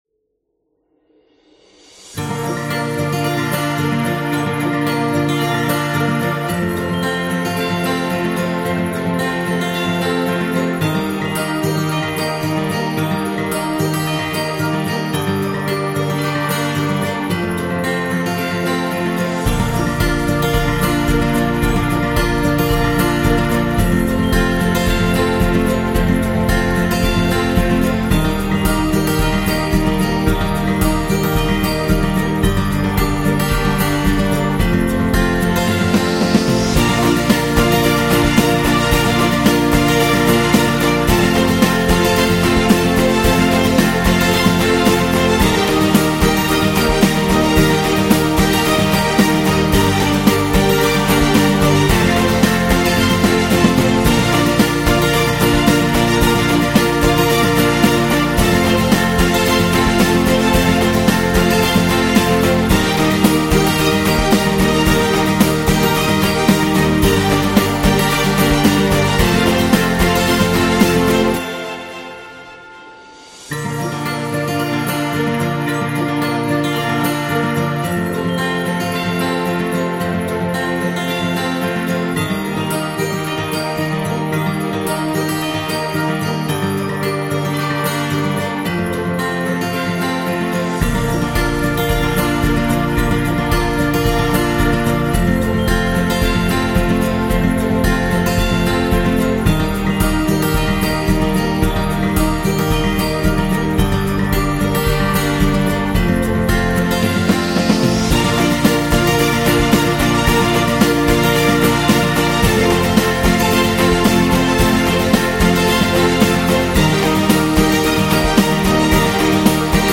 Uplifting, playful, and heartwarming